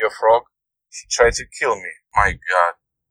angry.wav